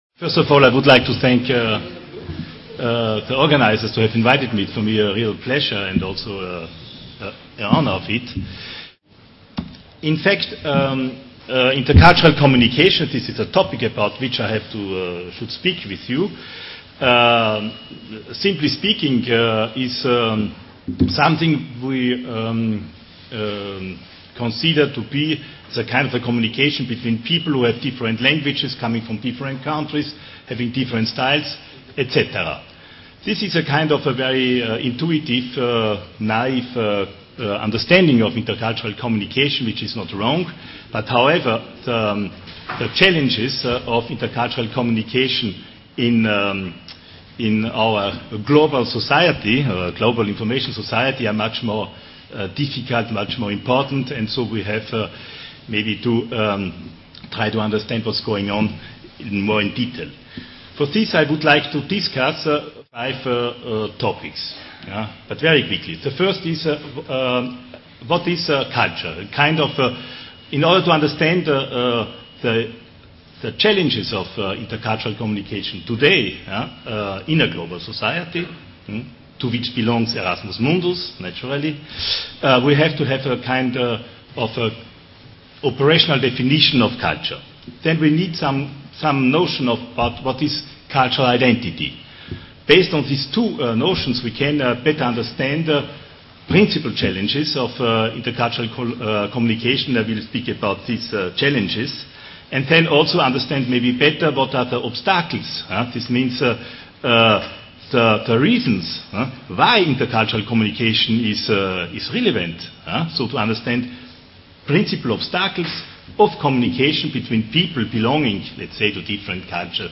La conférence a été donnée à l'Université Victor Segalen Bordeaux 2 à l’occasion de la conférence annuelle Alumni Erasmus Mundus le 29 mars 2010.